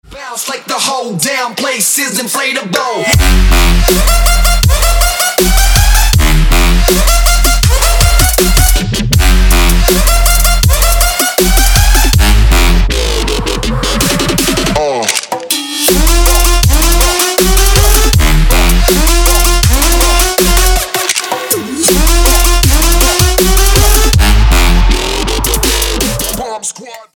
Trap
Bass
Hardstyle